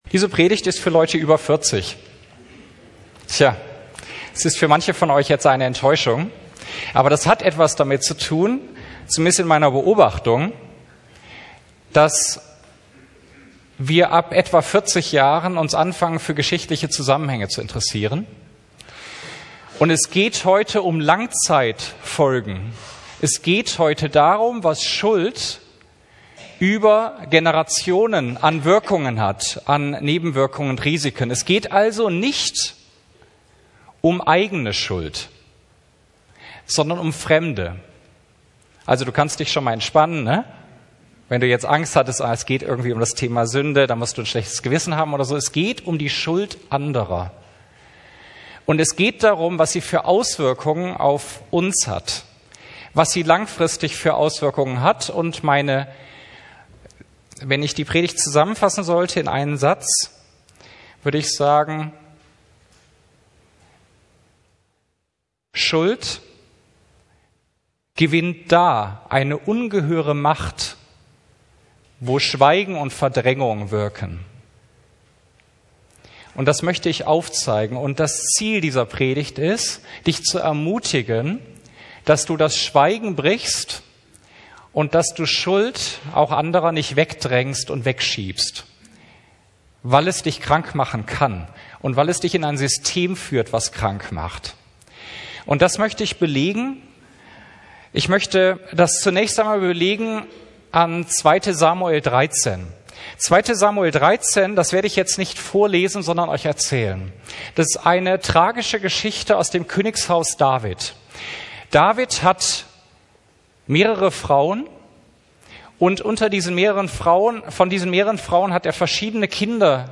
Predigtarchiv der FeG Friedberg
Diese Webseite will einen eine Auswahl der Predigten bereit stellen, die in der FeG in Friedberg gehalten wurden.